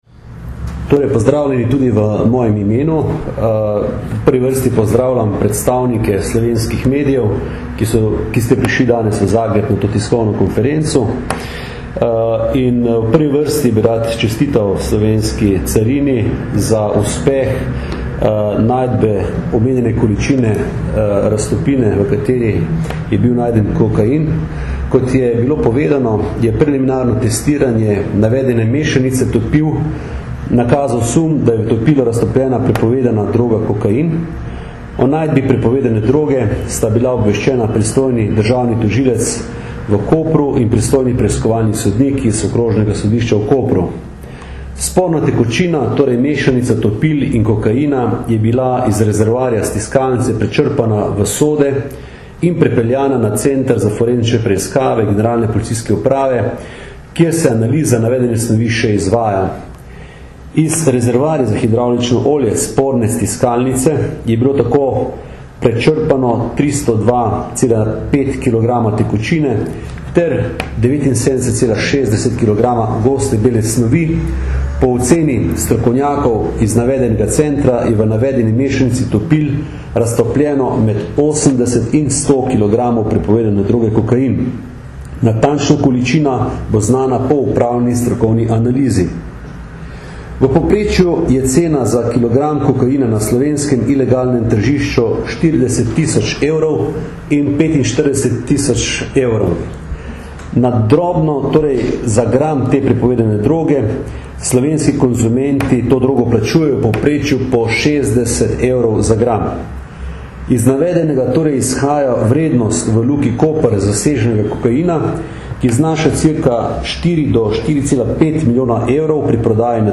V Luki Koper zasegli večjo količino kokaina - informacija s skupne novinarske konference slovenske in hrvaške policije ter carine
Zvočni posnetek izjave mag. Aleksandra Jevška (mp3)